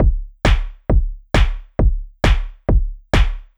GAR Beat - Mix 3.wav